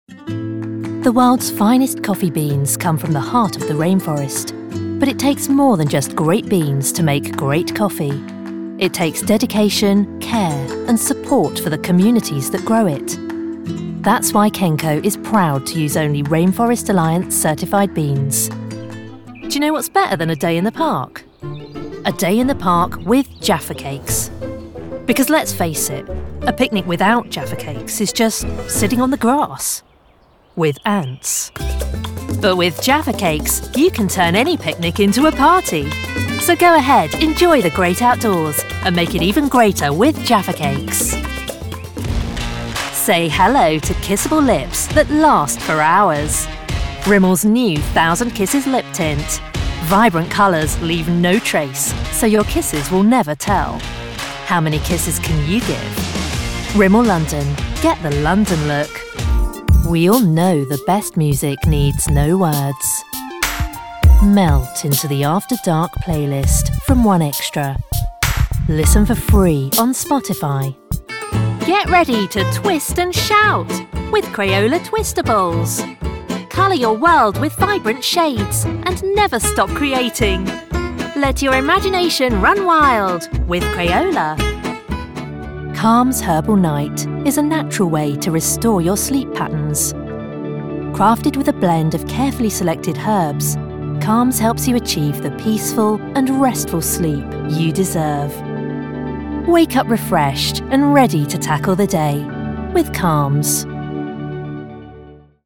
Full time British female voiceover with warm, friendly, bright and reassuring voice. Professional recording studio for perfect audio every time.
Sprechprobe: Werbung (Muttersprache):